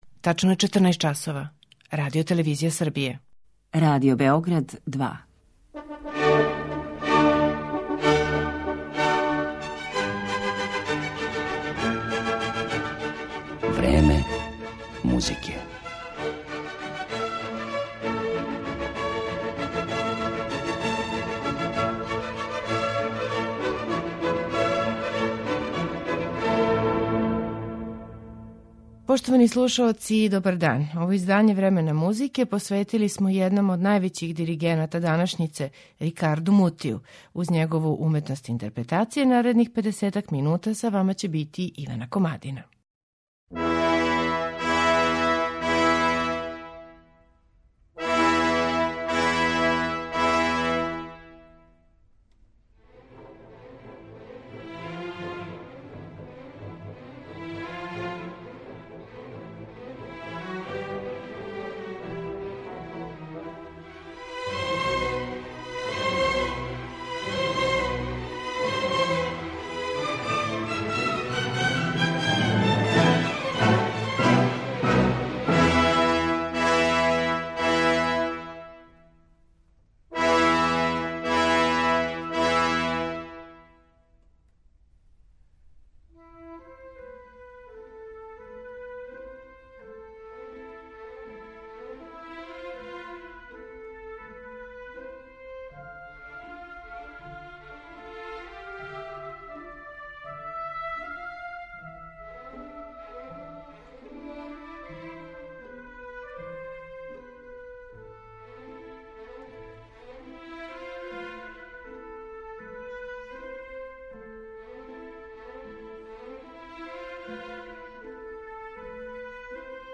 У Мутијевој интерпретацији слушаћете дела Ђузепа Вердија, Јозефа Хајдна, Руђера Леонкавала, Волфганга Амадеуса Моцарта, Емануела Шабријеа и Петра Чајковског.